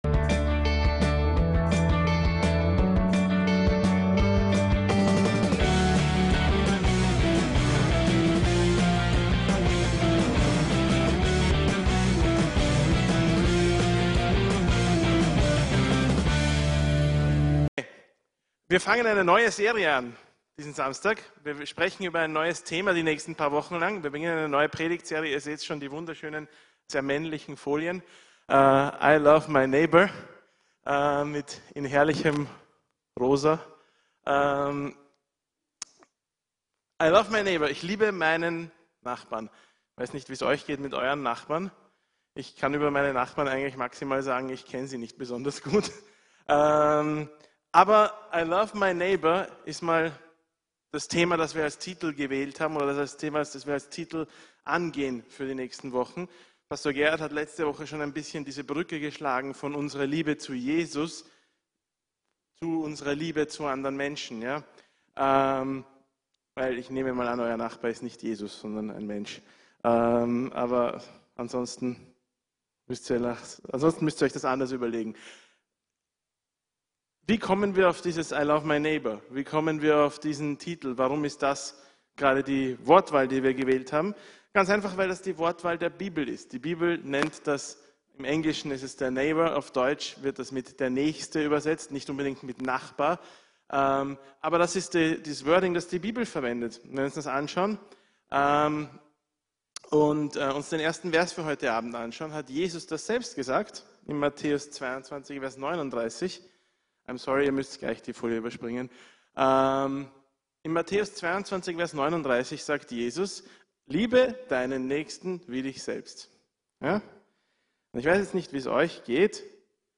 VCC JesusZentrum Gottesdienste